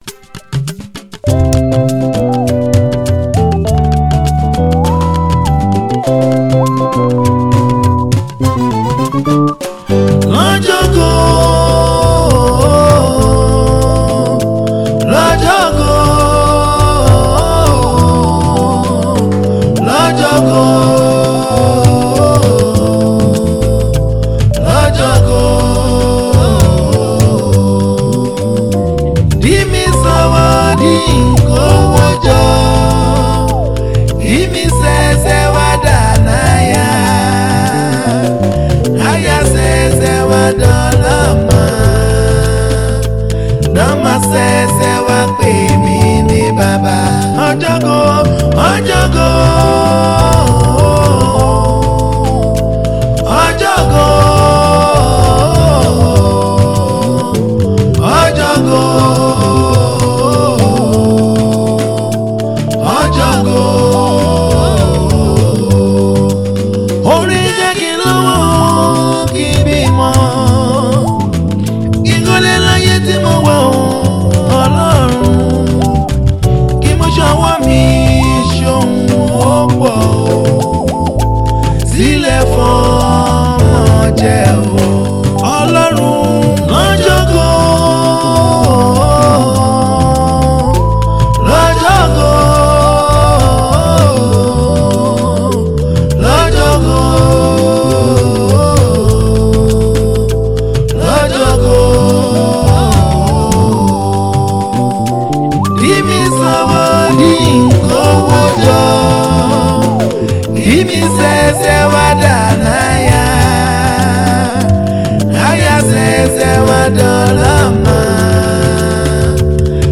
soulful and reflective track
The lyrics carry a deep emotional tone